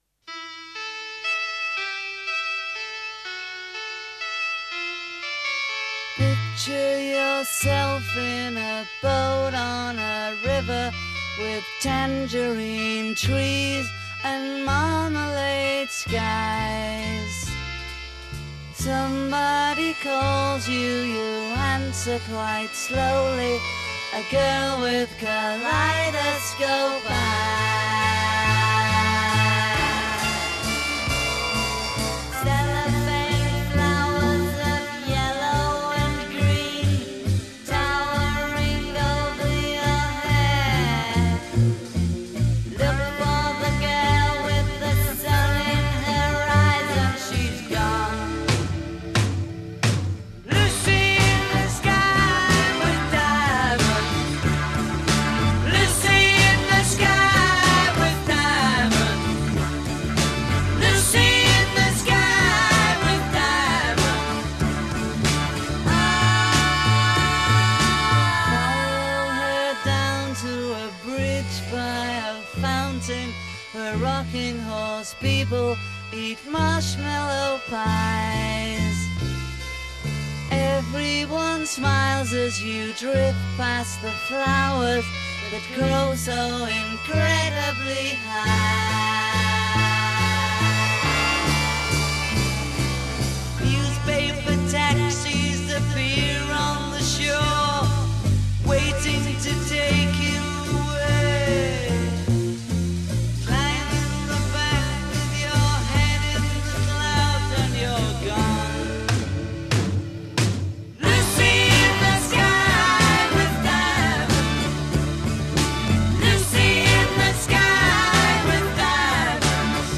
Recorded 1 March 1967 in Abbey Road, Studio 2.
Chorus C   12 Doubled vocal breaking into 2-part harmony. c
Chorus C     2-part vocal harmonies over 4/4. c
Repeat and fade. c